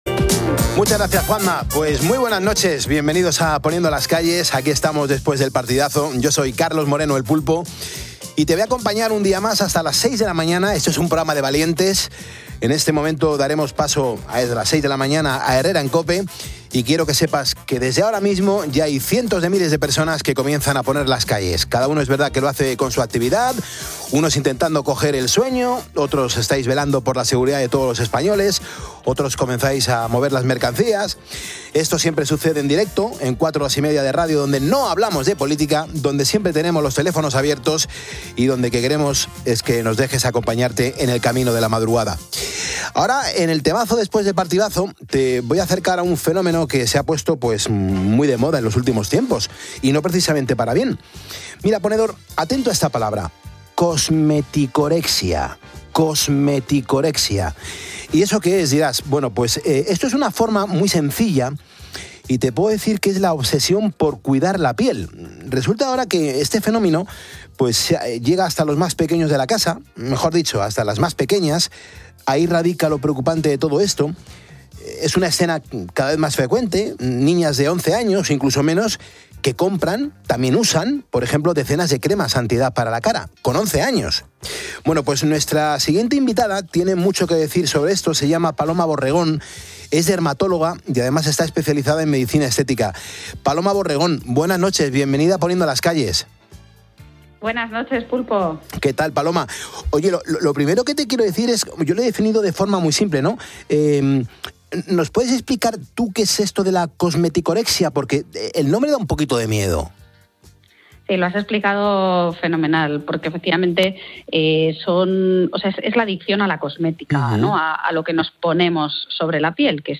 programa ' Poniendo las Calles ' de COPE